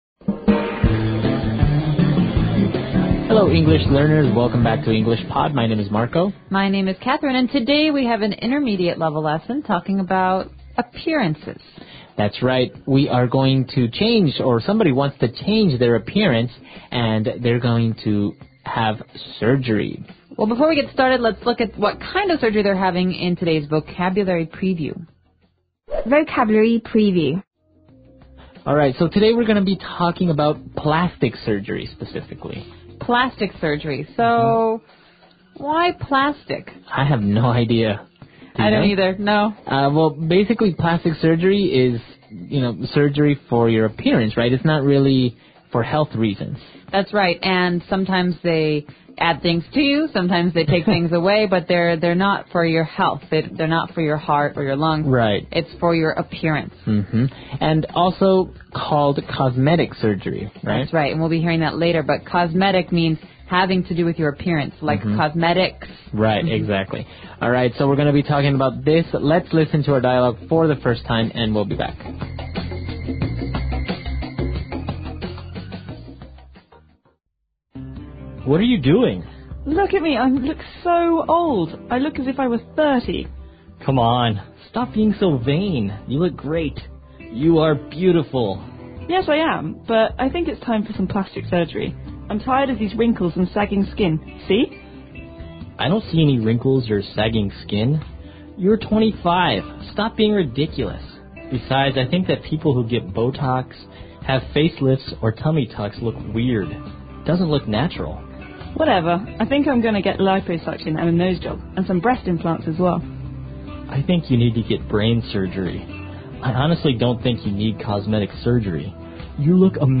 纯正地道美语(外教讲解)164：我要去整容 听力文件下载—在线英语听力室